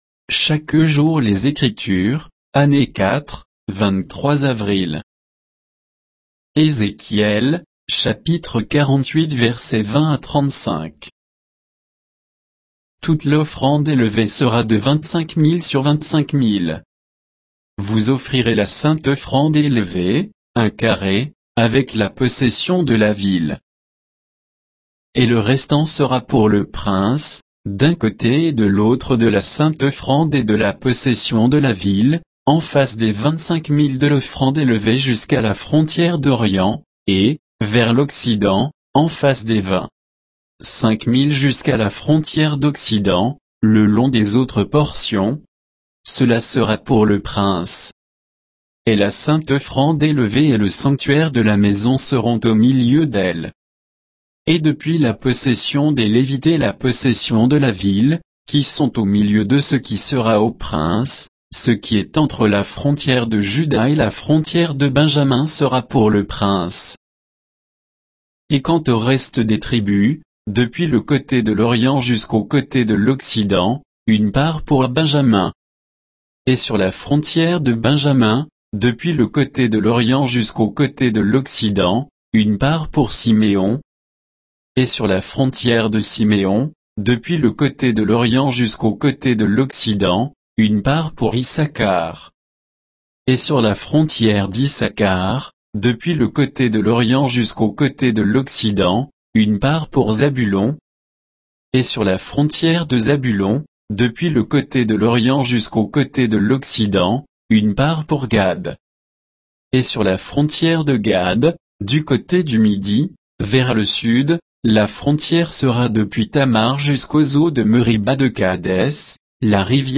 Méditation quoditienne de Chaque jour les Écritures sur Ézéchiel 48